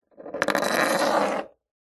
Звук падения волчка